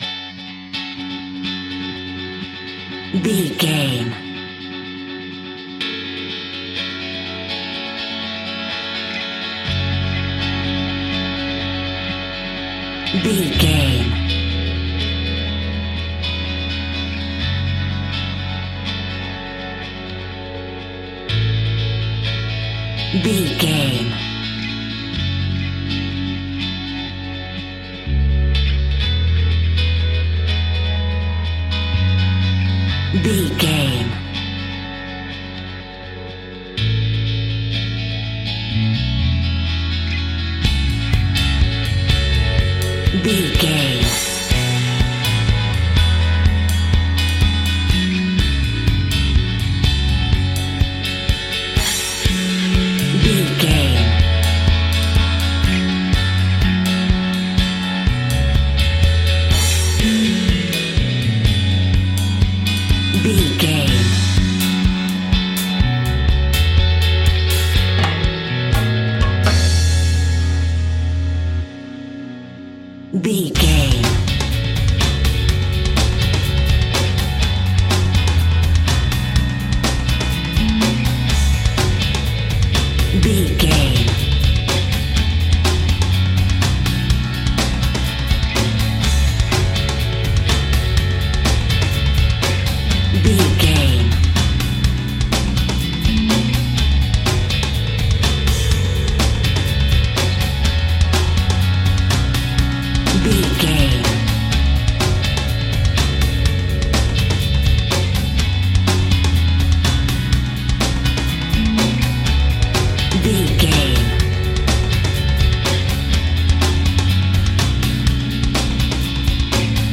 Aeolian/Minor
D♭
cool
uplifting
bass guitar
electric guitar
drums
cheerful/happy